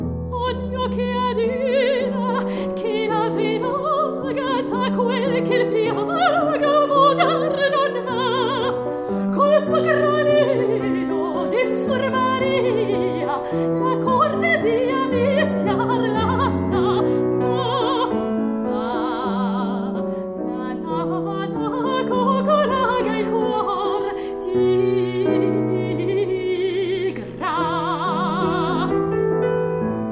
soprano
pianiste
"templateExpression" => "Musique classique"